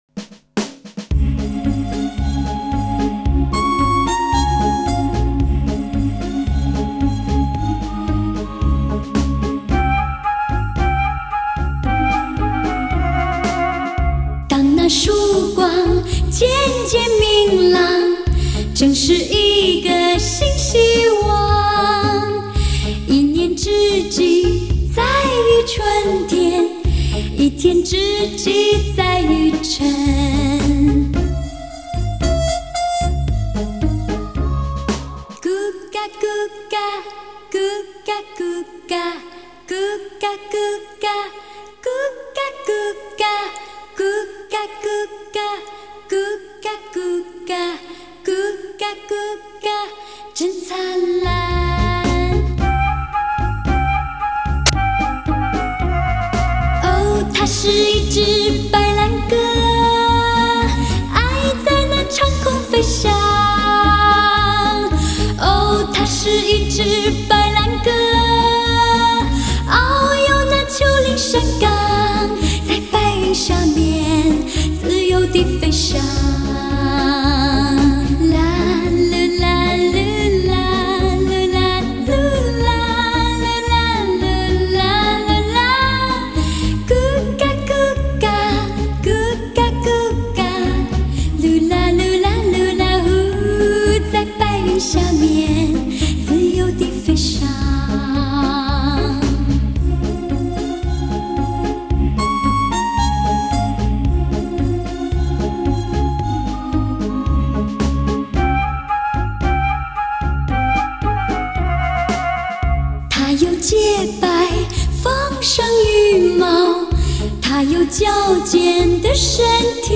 还原声音本质 超强平衡细腻
多点定位人声试音典范
天籁合声